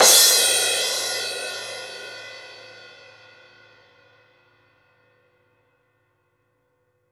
Index of /90_sSampleCDs/Total_Drum&Bass/Drums/Cymbals
crash_cym3.wav